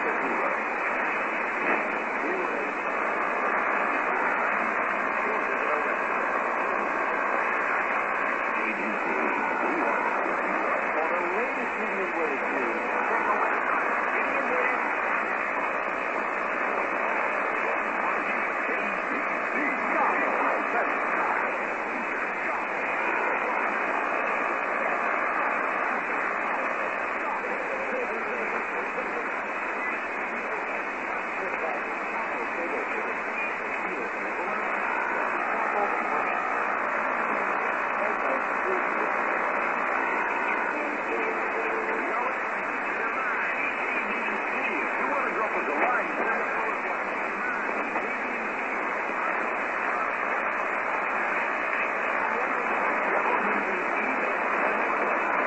ID(Mighty KBC)@18" weak